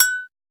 washboard_a.ogg